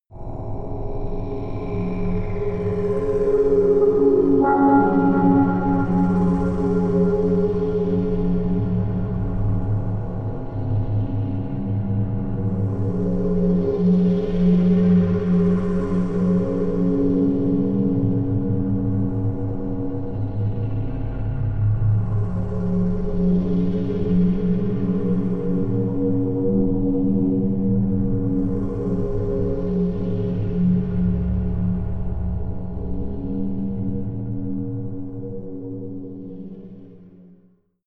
Gemafreie Sounds: Weltraum